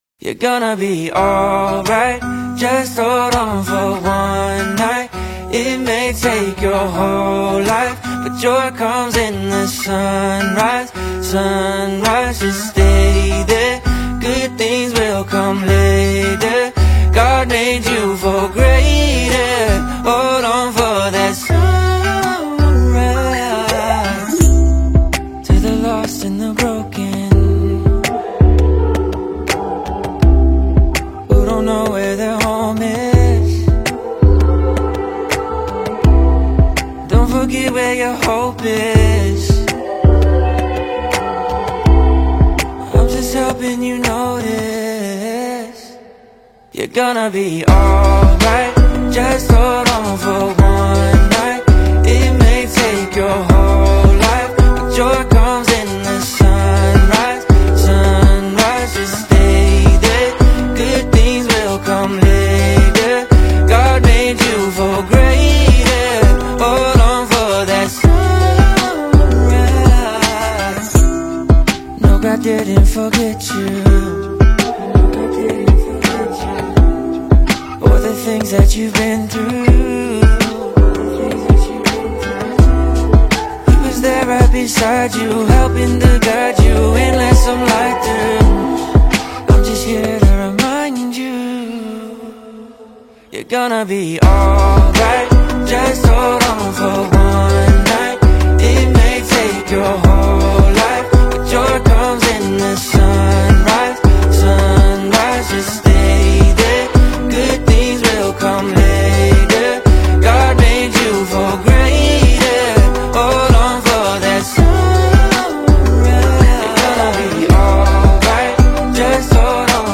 restorative minimalism
warm, lo-fi hip-hop beats
Gospel Songs